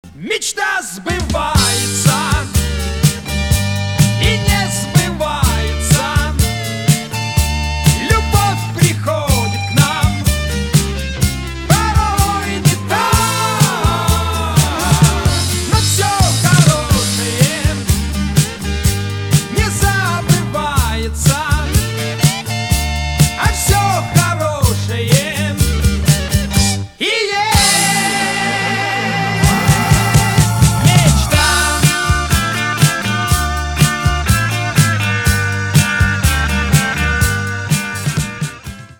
• Качество: 320, Stereo
гитара
позитивные
добрые
80-е